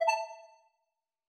arcade-move.mp3